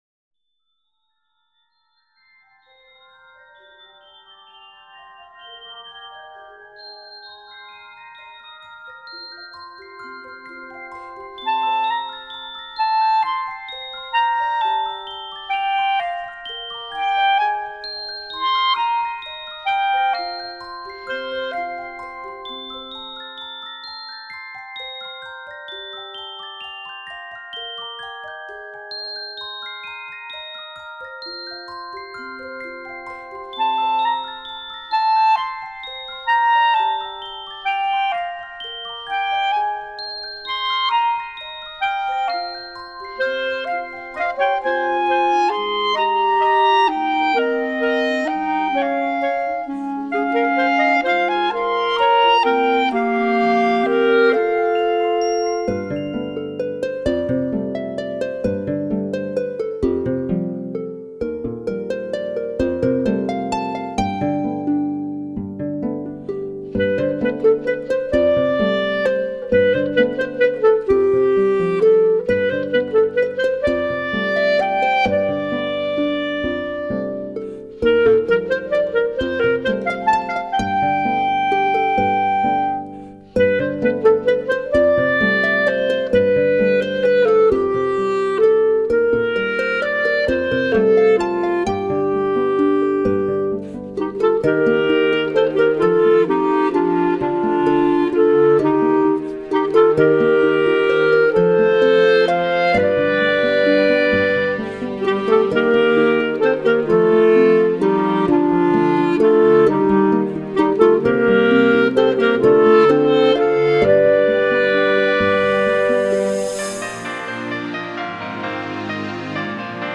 笛子
小提琴
大提琴
單簧管
鋼琴
穿越时空的超狂想音乐。
CD1超优质童话音乐伴随幼儿入好眠